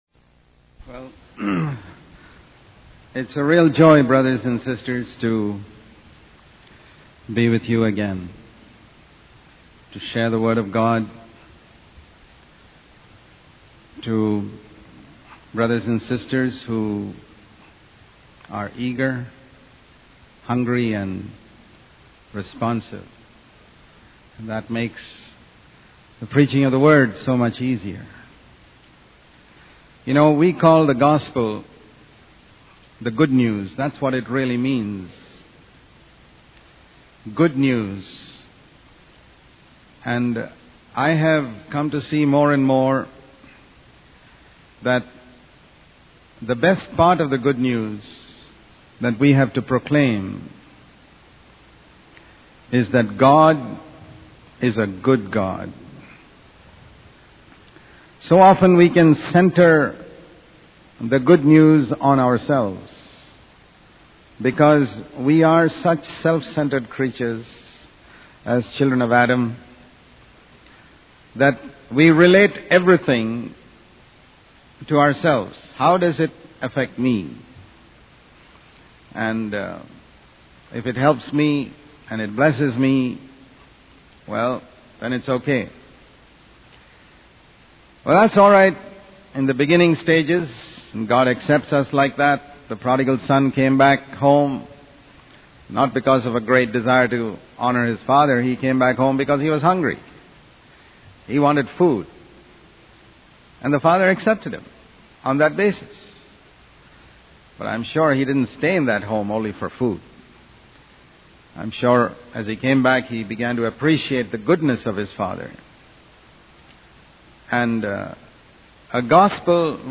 In this sermon, the preacher emphasizes the importance of understanding and proclaiming that God is a good God. He highlights how often we can have a self-centered perspective, only focusing on how things affect us.